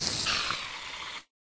spiderdeath.ogg